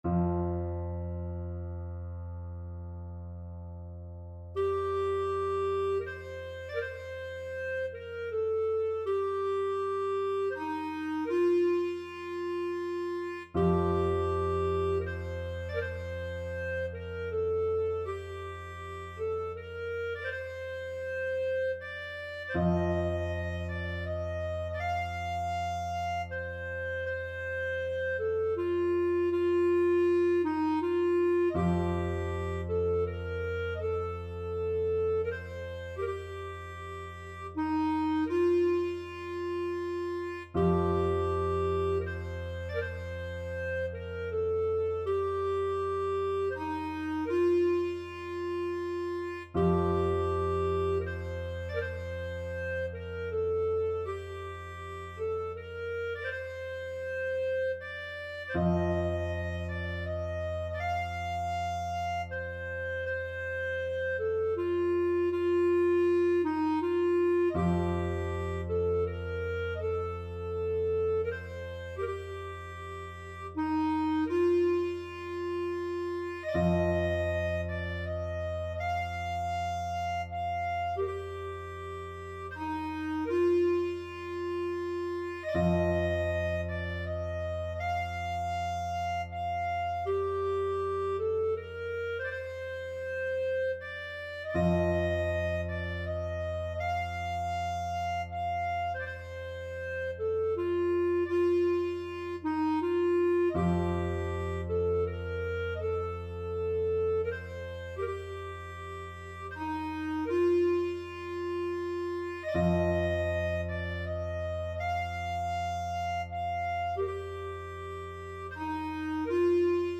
Clarinet
A beautiful lament, as played by a lone piper at the end of the funeral of Queen Elizabeth II at Westminster Abbey on 19th September 2022.
Bb major (Sounding Pitch) C major (Clarinet in Bb) (View more Bb major Music for Clarinet )
Slowly and freely, in the manner of bagpipes =80
3/4 (View more 3/4 Music)
Traditional (View more Traditional Clarinet Music)